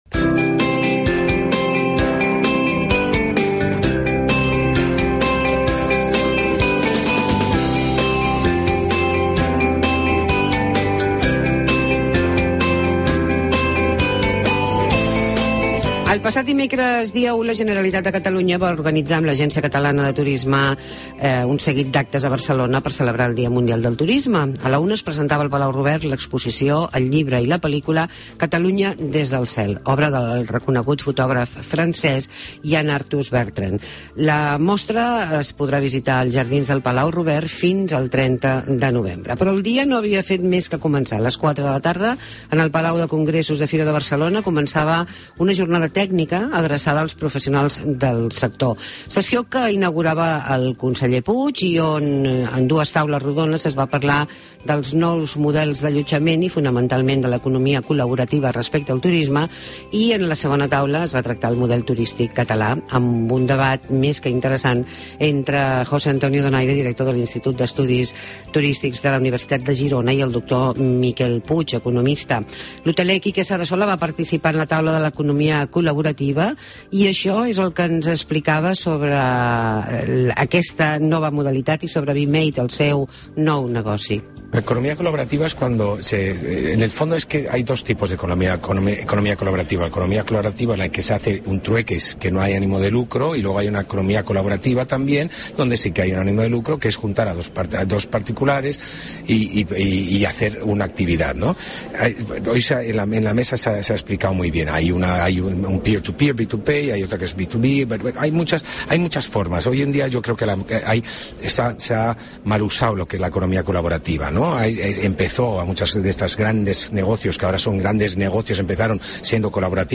Reportatge sobre la celebració del Dia del Turisme i La Nit del Turisme